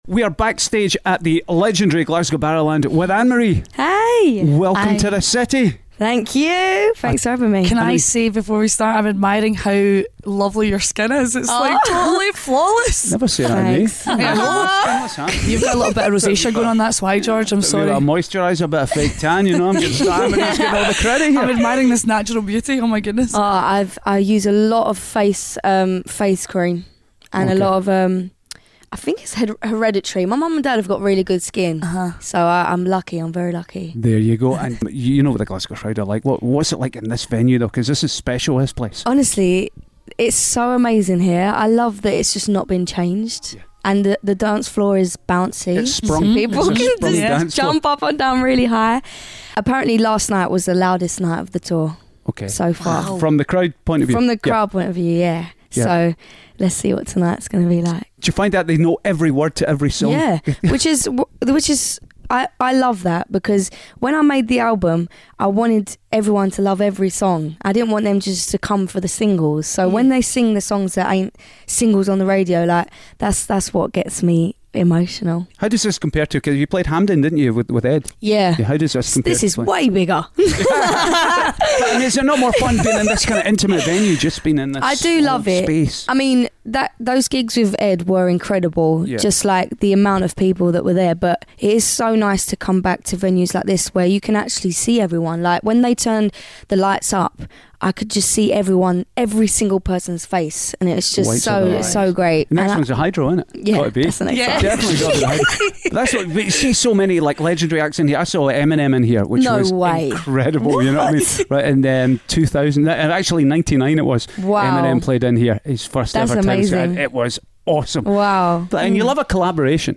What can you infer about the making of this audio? backstage at The Barrowlands...